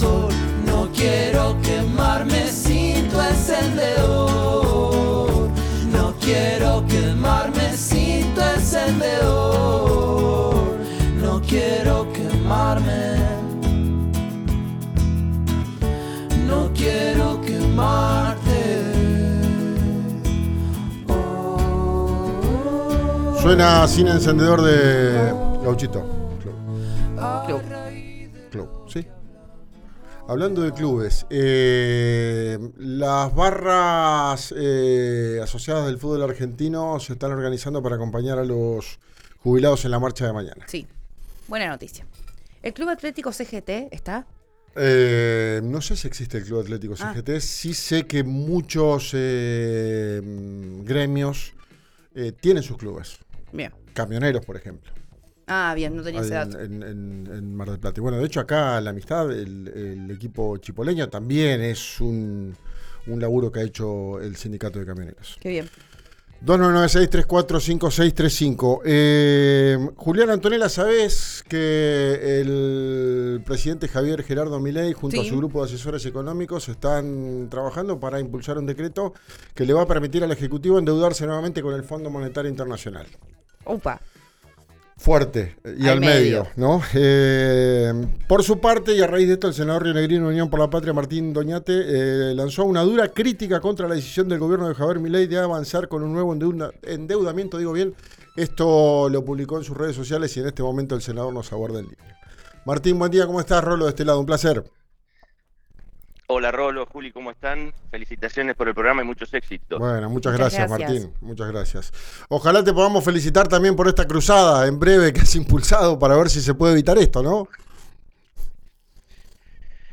Escuchá al senador Martín Doñate, por RÍO NEGRO RADIO: